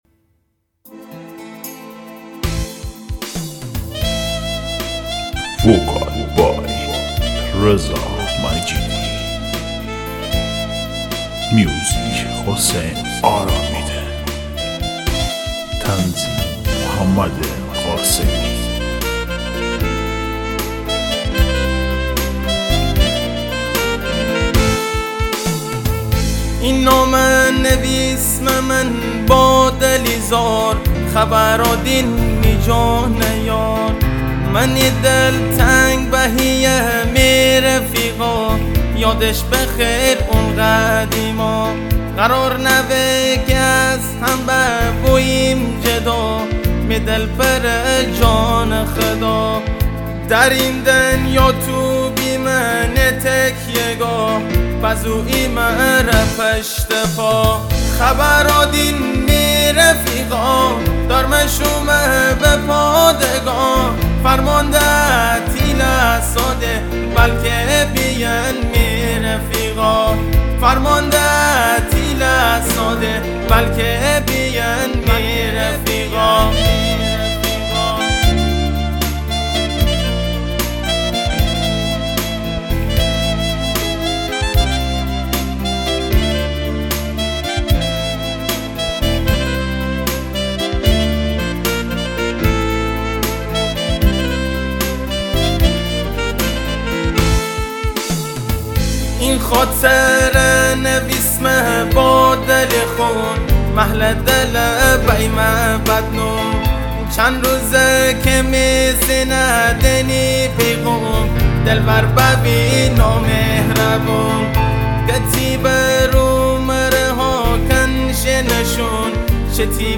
دانلود آهنگ مازندرانی جدید
آهنگ شاد